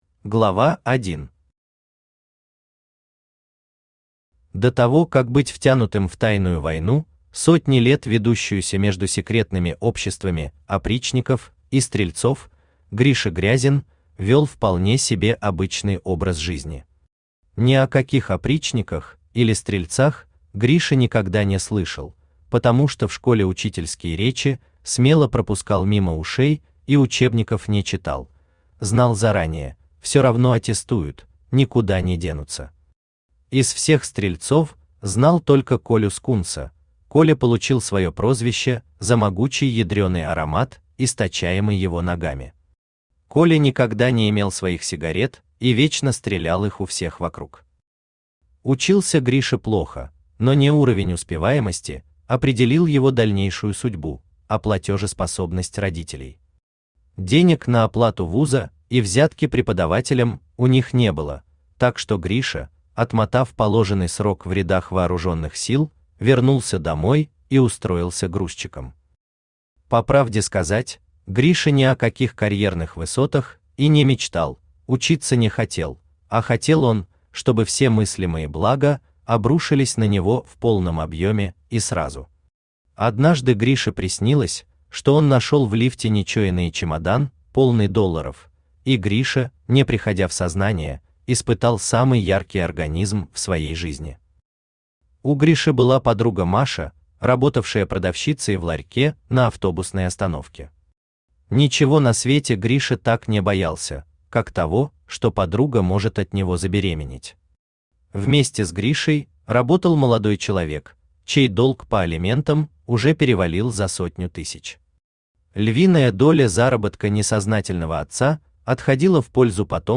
Аудиокнига Кредо холопа | Библиотека аудиокниг
Aудиокнига Кредо холопа Автор Сергей Александрович Арьков Читает аудиокнигу Авточтец ЛитРес.